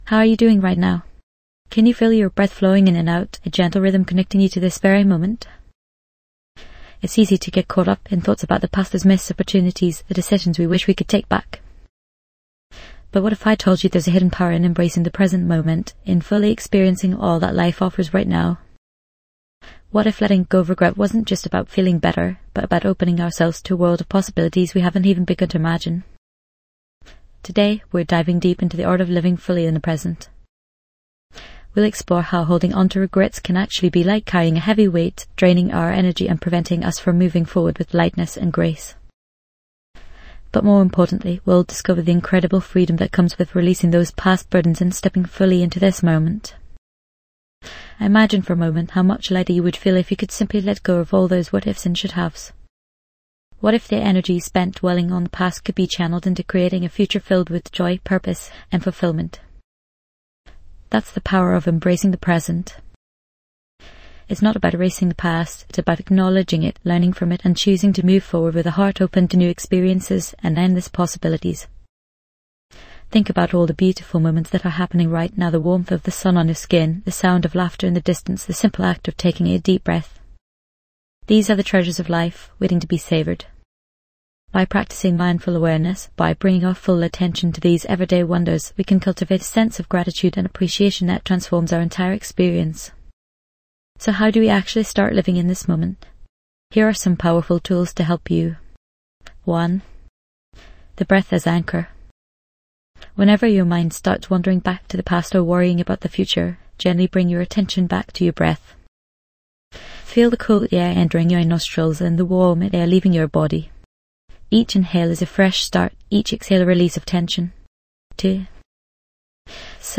This podcast offers guided meditations, insightful reflections, and inspiring stories interwoven with wisdom from various faith and dharma traditions. Each episode is a sanctuary for your mind, helping you cultivate stillness, reduce anxiety, and connect with a deeper sense of purpose.